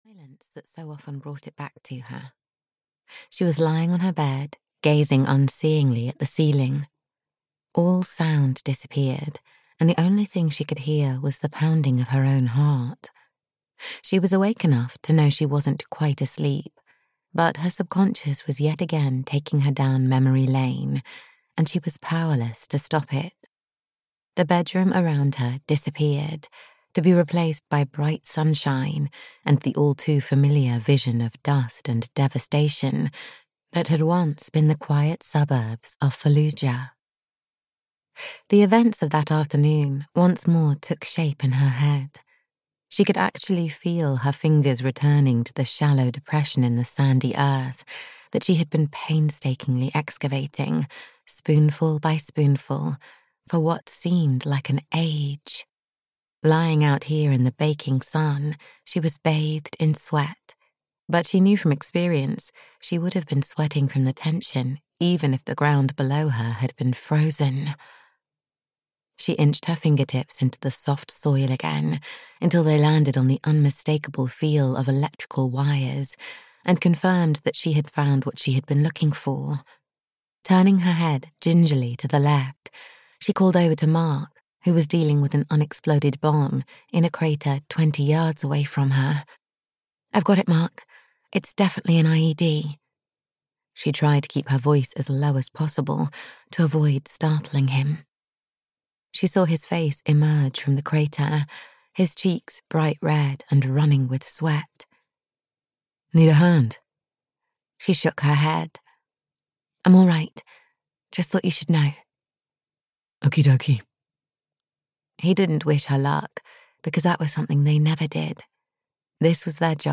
A Chance in a Million (EN) audiokniha
Ukázka z knihy